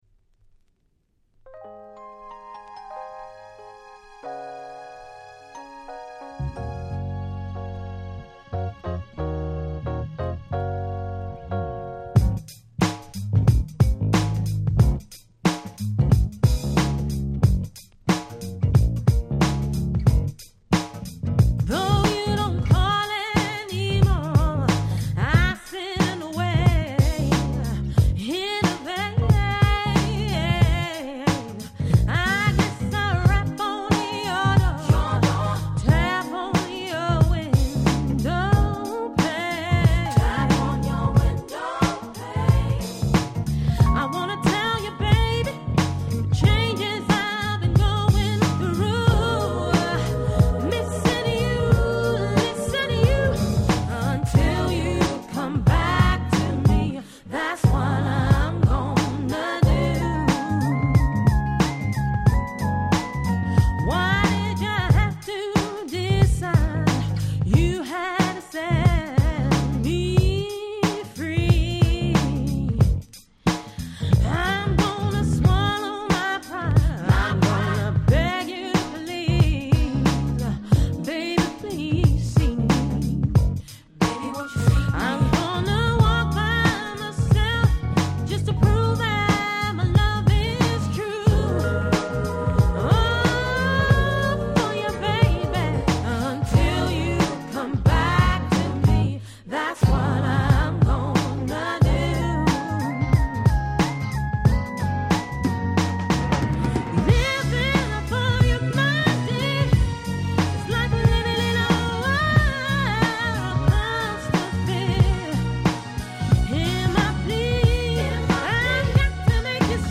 頭から尻尾の先までNiceなUK Soulがてんこ盛り！